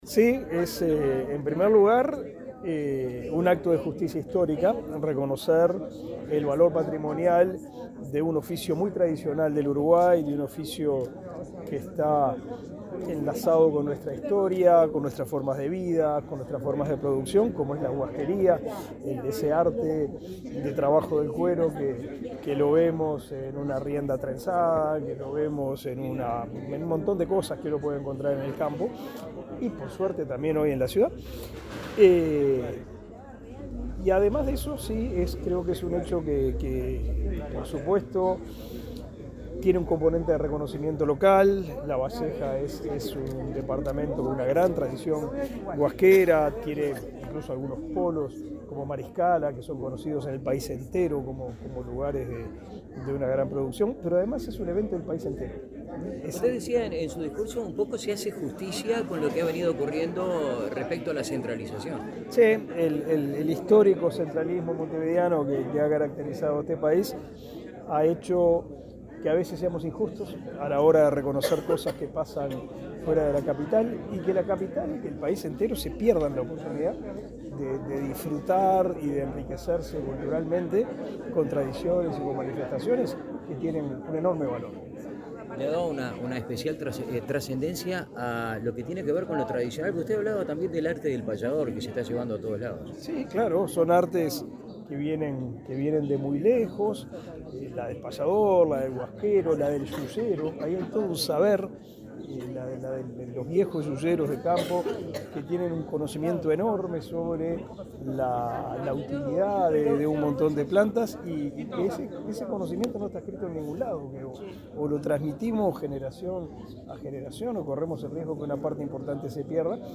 Declaraciones a la prensa del ministro de Educación y Cultura, Pablo da Silveira
Luego, dialogó con la prensa.